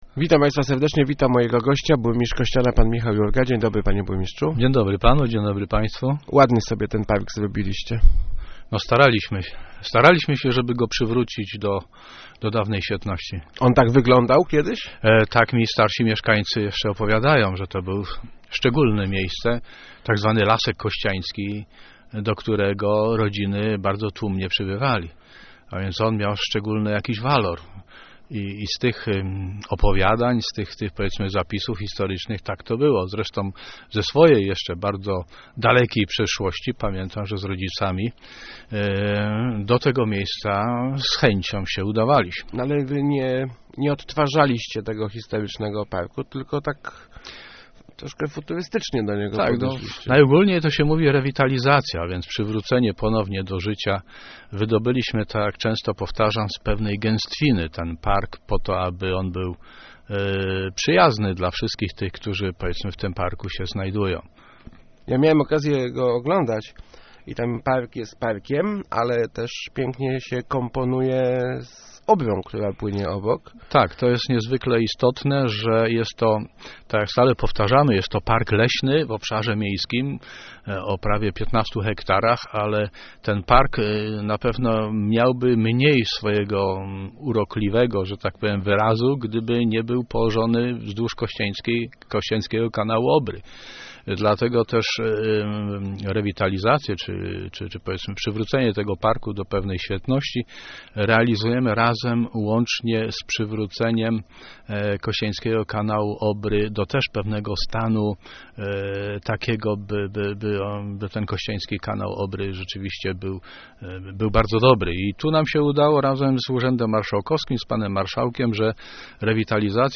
jurga80.jpg-To jeszcze nie wszystko - mówił w Rozmowach Elki burmistrz Kościana Michał Jurga, oceniając oddanie do użytku parku im. Kajetana Morawskiego. Już wkrótce gotowa będzie zrewitalizowana wieża ciśnień, najbliższe plany obejmują też kręgielnie i dokończenie odnowy koryta Obry.